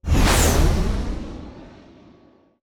Sci-Fi Effects
engine_flyby_001.wav